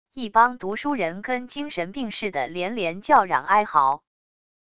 一帮读书人跟精神病似的连连叫嚷哀嚎wav音频